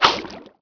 1 channel
meleemiss2.wav